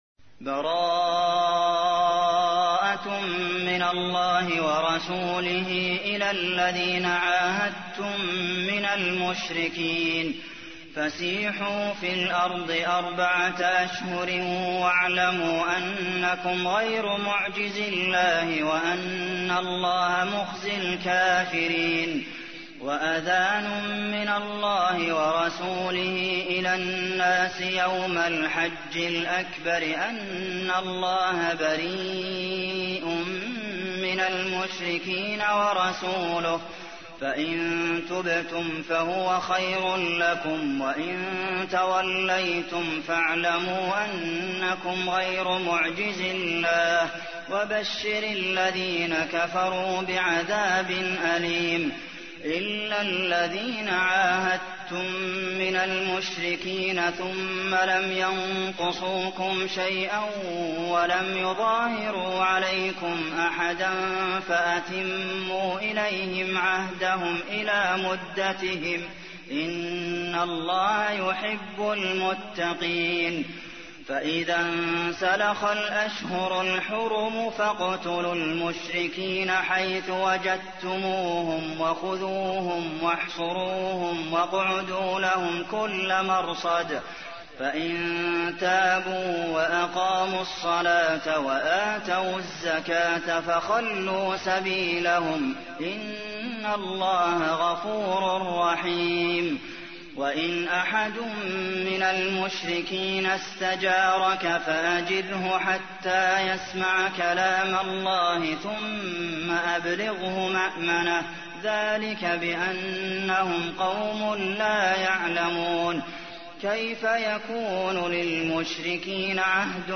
تحميل : 9. سورة التوبة / القارئ عبد المحسن قاسم / القرآن الكريم / موقع يا حسين